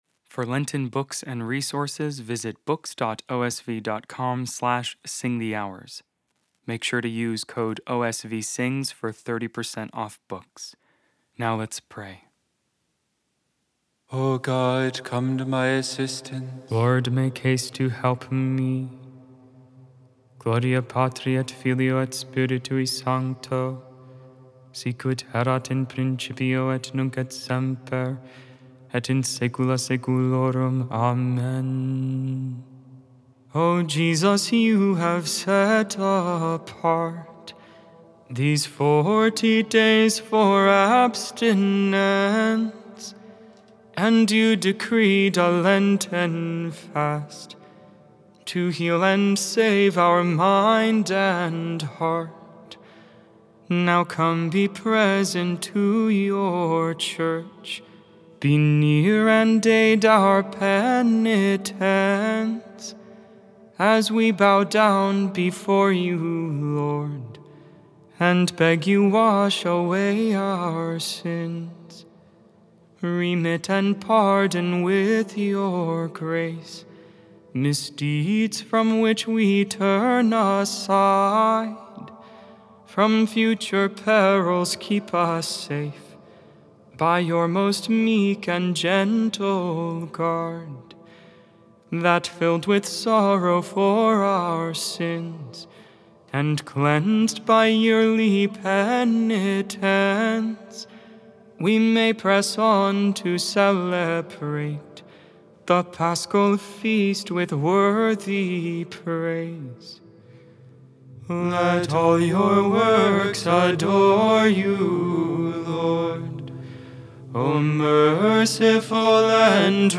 3.5.25 Vespers, Wednesday Evening Prayer of the Liturgy of the Hours